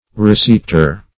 Receiptor \Re*ceipt"or\ (r[-e]*s[=e]t"[~e]r), n.